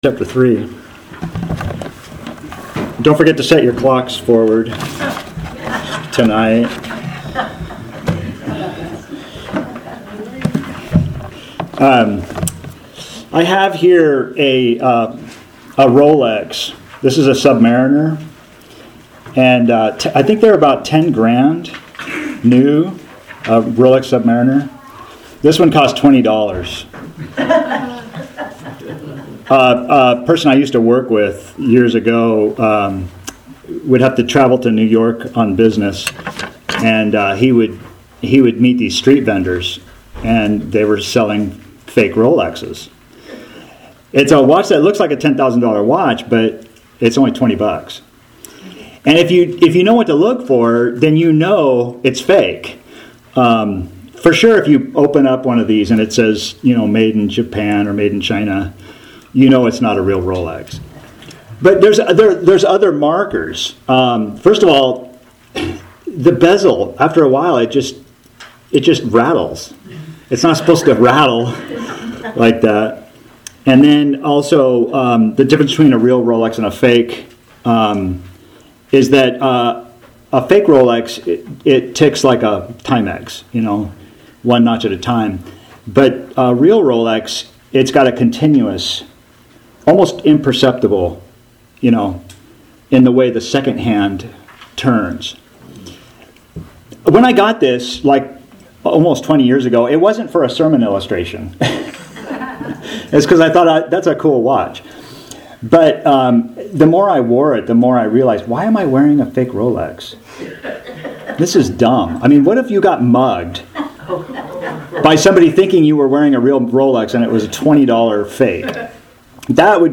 TEXT: Proverbs 1:20-33 | AUDIO | TEXT PDF Author jstchurchofchrist Posted on March 21, 2025 May 11, 2025 Categories Sermons Tags Proverbs , Wisdom from Above Who is Wise and Understanding? Is your wisdom from above, or from the abyss?